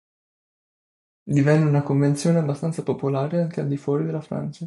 fuò‧ri
/ˈfwɔ.ri/